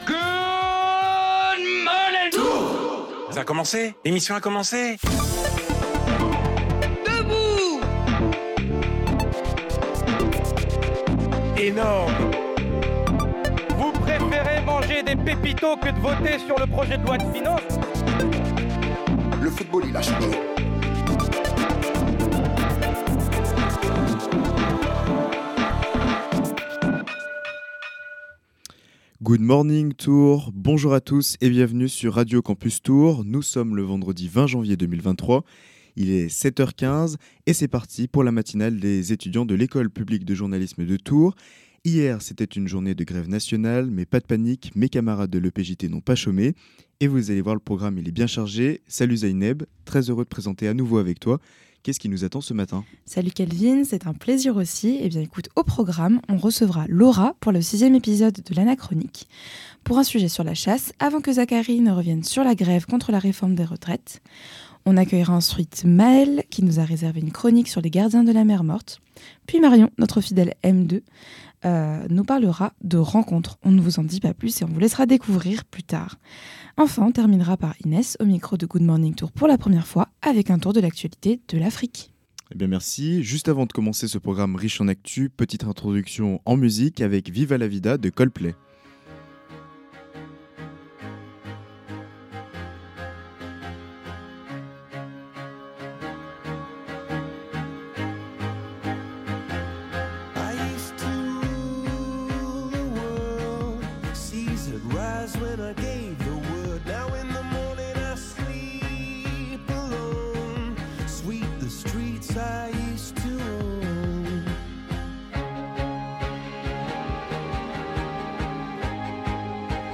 La matinale des étudiants de l’École Publique de Journalisme de Tours, le vendredi de 7h15 à 8h15 .